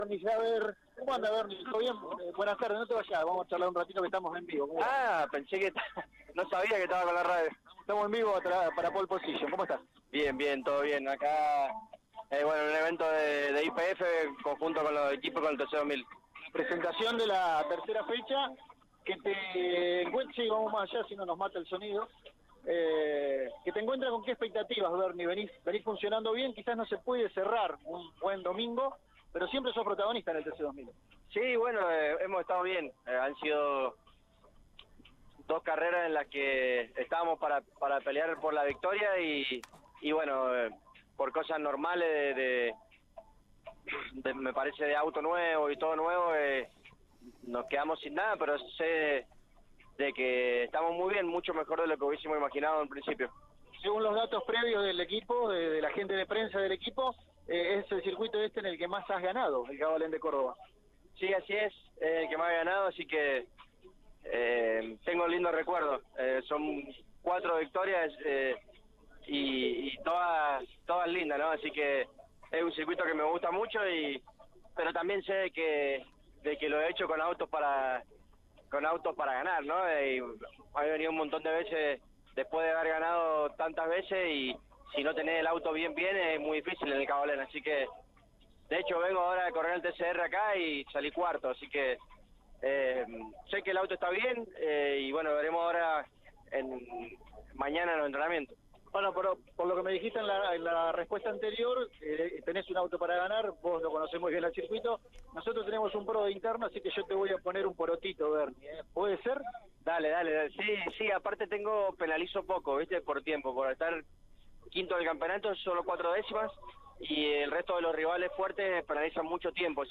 El mendocino pasó por los micrófonos de Pole Position y habló en la previa a la presentación de la competencia de Alta Gracia, manifestándose confiado con el funcionamiento del auto y del equipo, en un circuito que lo vio ganador en cuatro ocasiones.